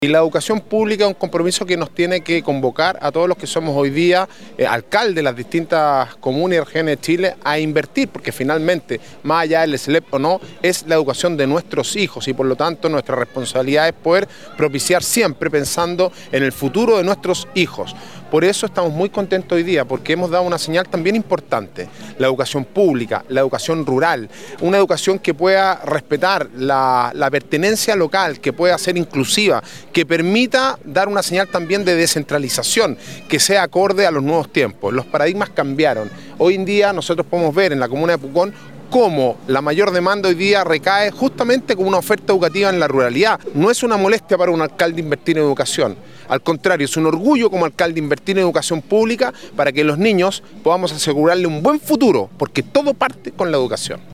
Alcalde-Sebastian-Alvarez-prioriza-la-inversion-en-Educacion.mp3